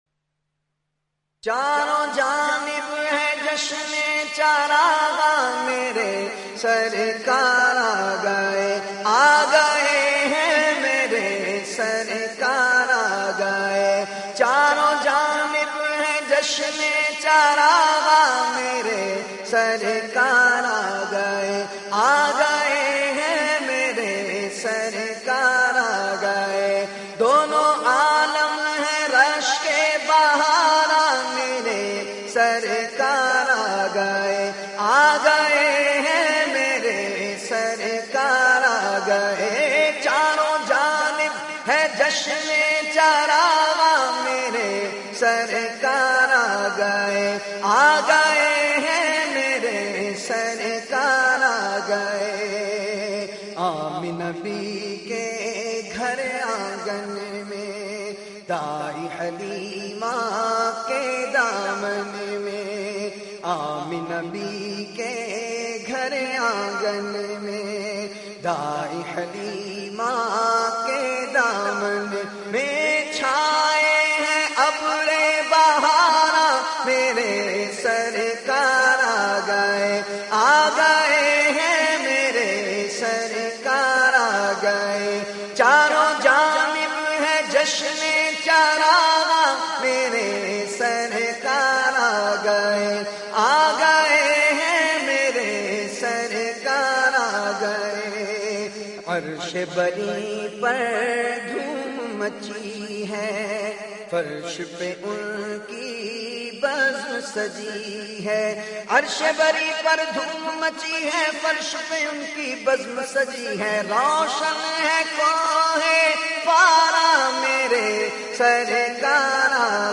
Category : Naat | Language : Urdu